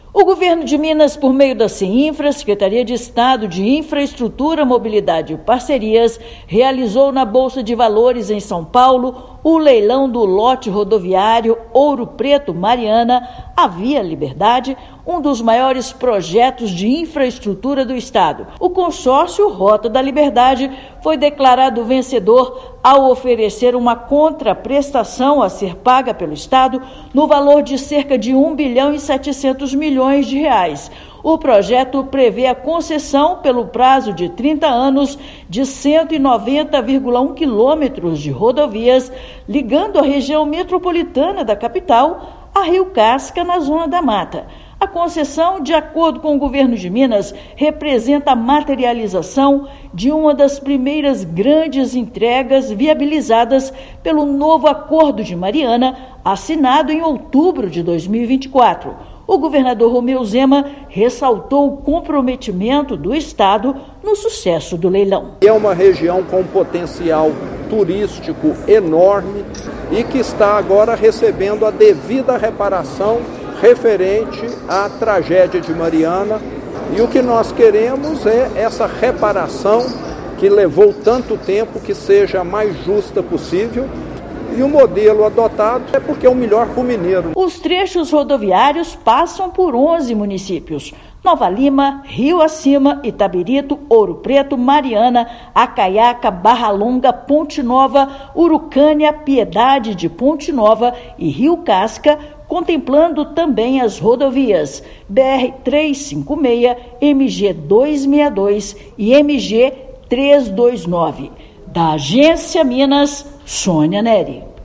[RÁDIO] Consórcio Rota da Liberdade vence leilão do lote rodoviário Ouro Preto – Mariana
Certame foi realizado na Bolsa de Valores, em São Paulo; concessão contempla duplicação de pistas e investimentos em segurança viária. Ouça matéria de rádio.